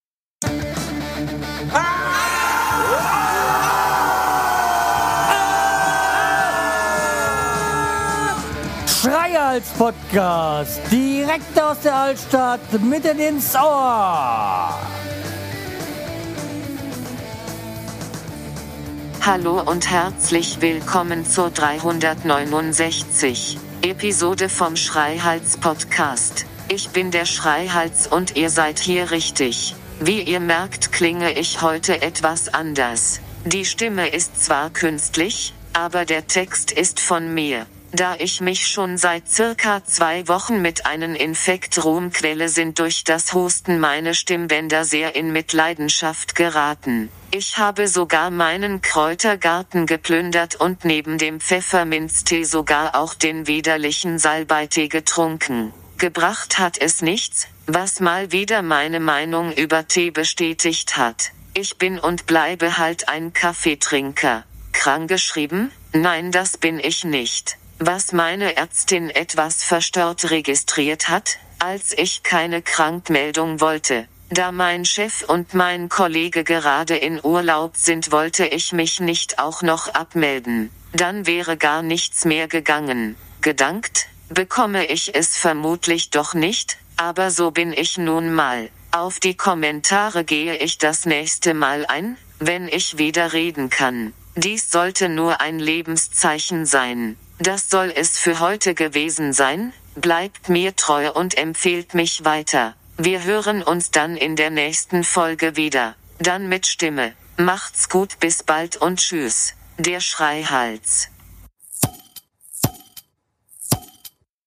Bis bald und mit Hilfe der Pharmaindustrie hoffentlich dann auch mit Stimme.